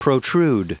Prononciation du mot protrude en anglais (fichier audio)
Prononciation du mot : protrude